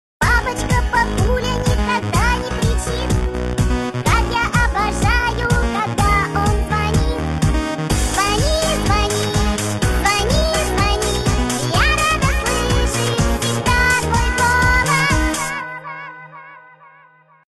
забавный голос
детский голос